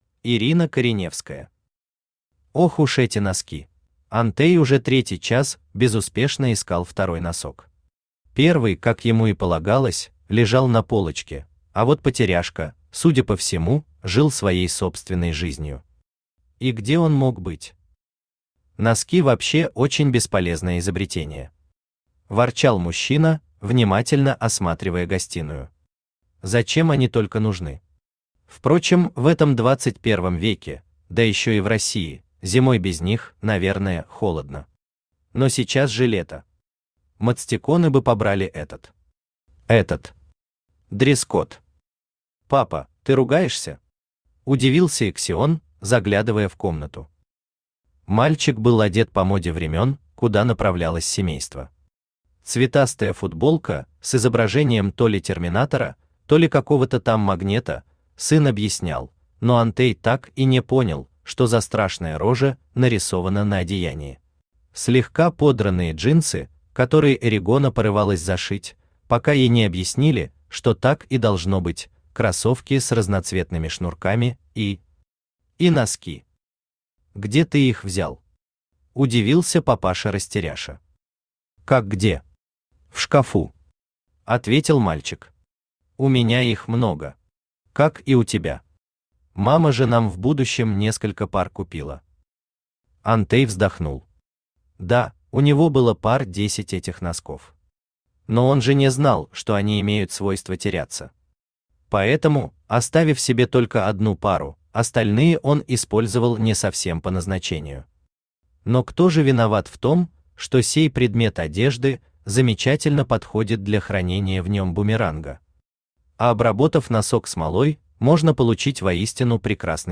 Аудиокнига Ох уж эти носки!
Автор Ирина Михайловна Кореневская Читает аудиокнигу Авточтец ЛитРес.